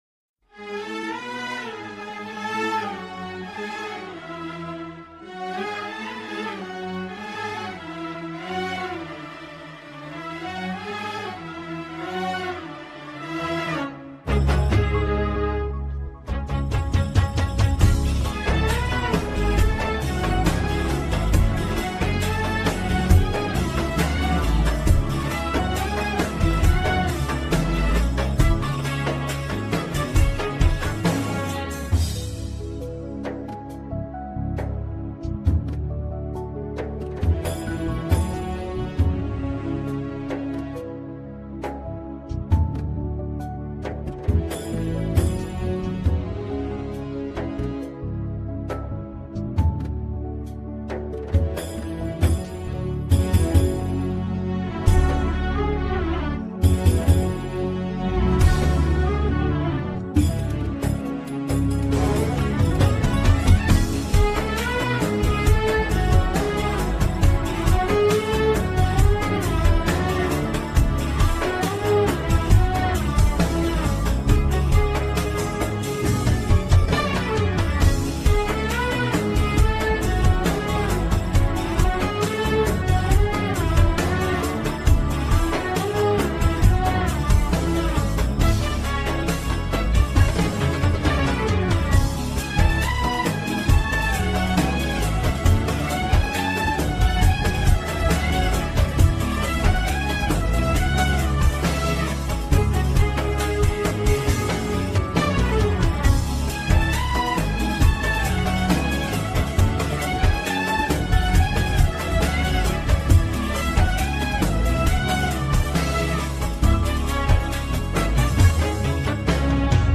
نسخه بی کلام